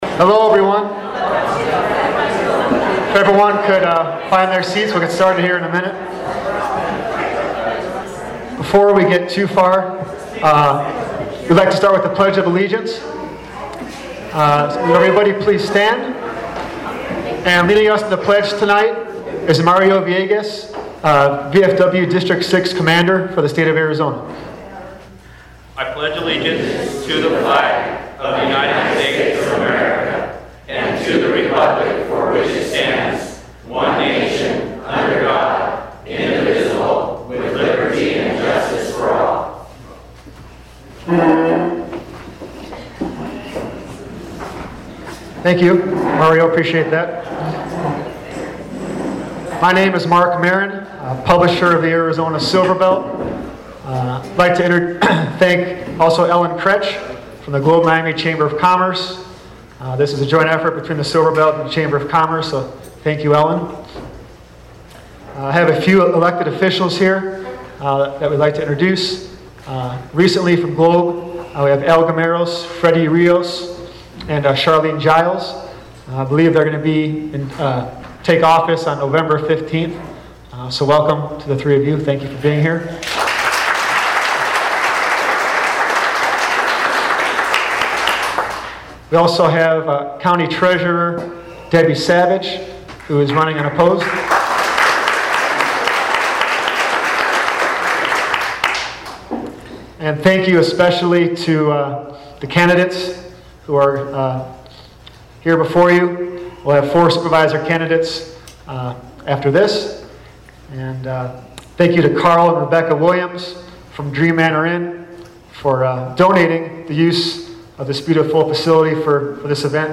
The candidates fielded several questions, with the incumbents standing on their records, and the challengers attempting to impress the audience with their history of community involvement and business acumen. KQSS recorded the entire event and has uploaded it for you to hear.